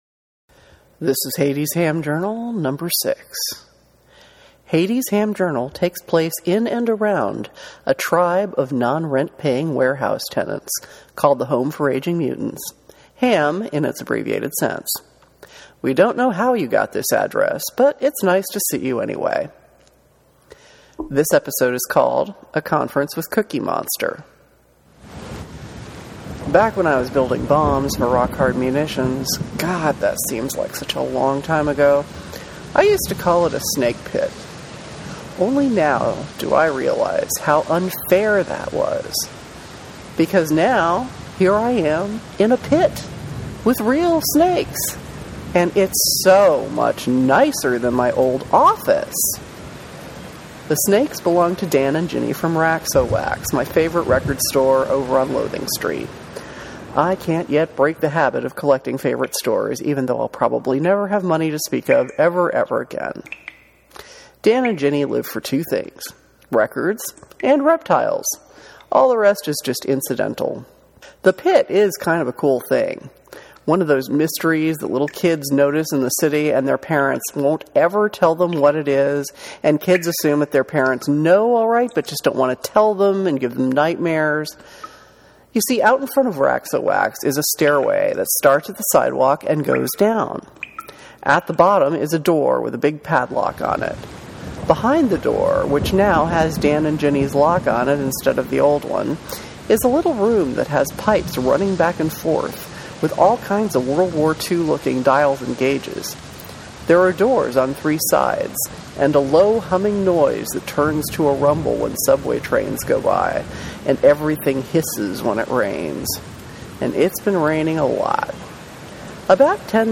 (oh and about the sound bed: you might want to make sure you go potty first)